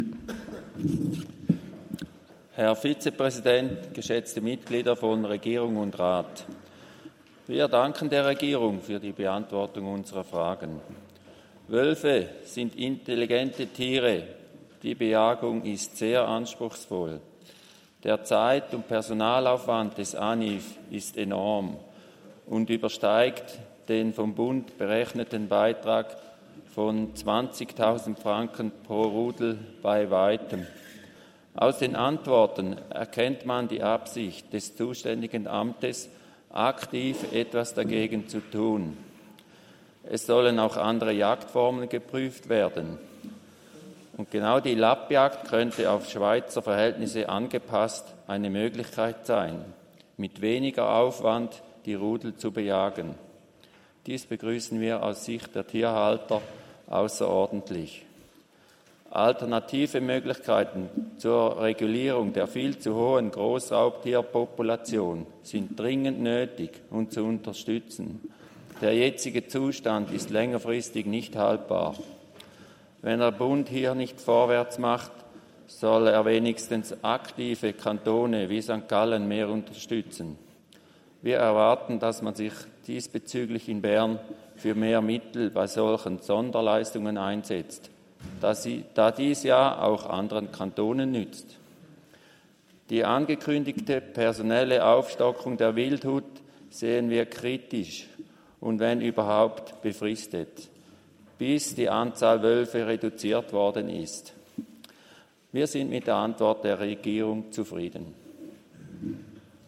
18.9.2024Wortmeldung
Session des Kantonsrates vom 16. bis 18. September 2024, Herbstsession